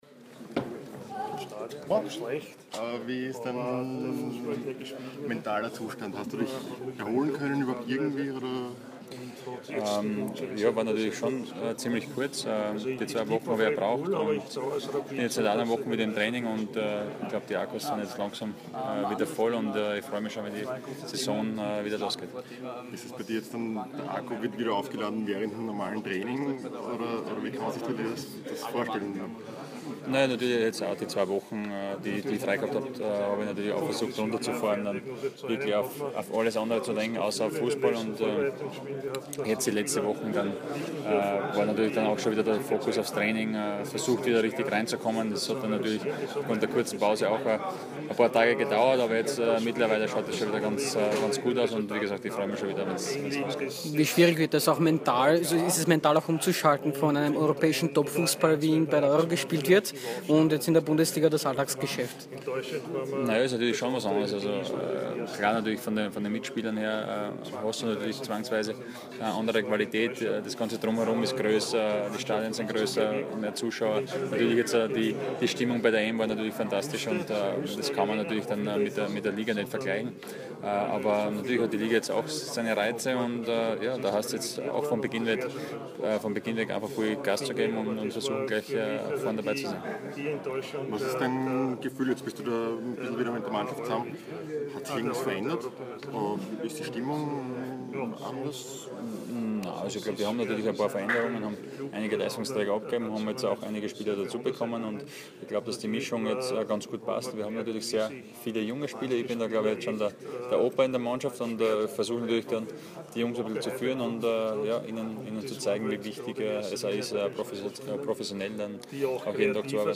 Robert Almer Interview